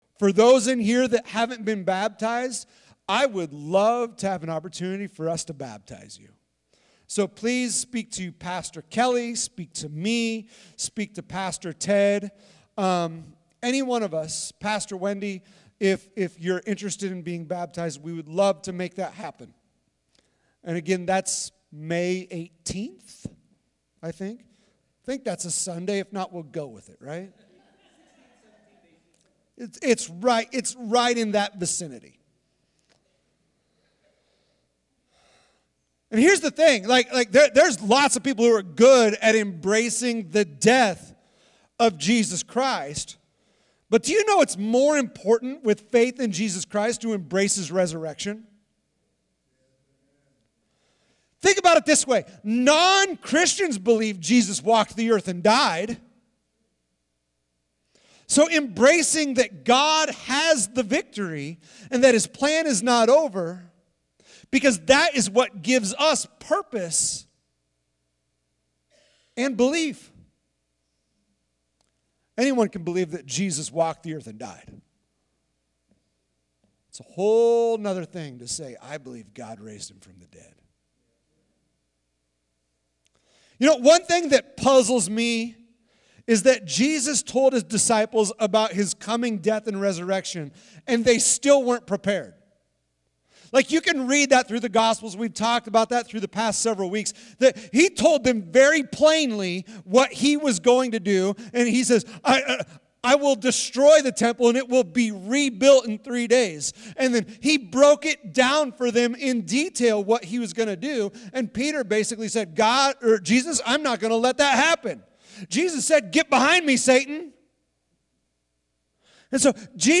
Sermons | Sunshine Open Bible Church